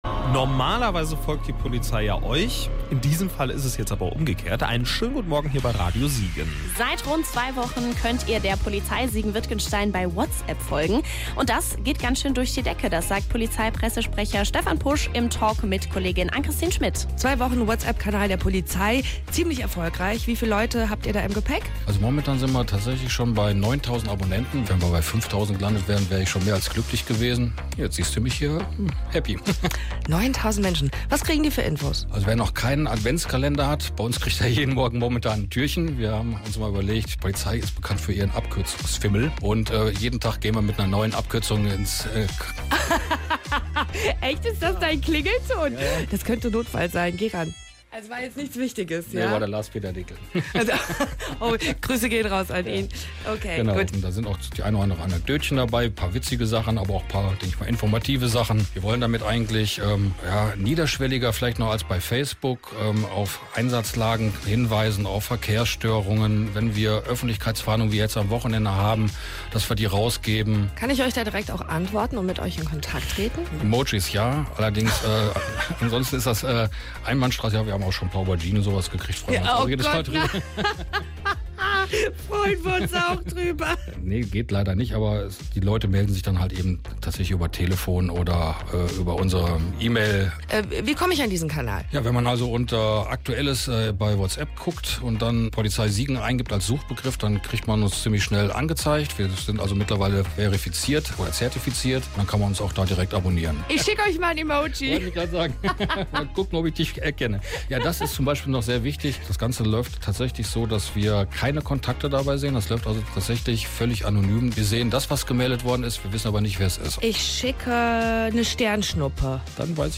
Das Interview zu unserem neuen WhatsApp-Kanal können Sie hier aufrufen.
si-audio-interview-radio-siegen-zum-neuen-wa-kanal-acs-ms-wa-polizei.mp3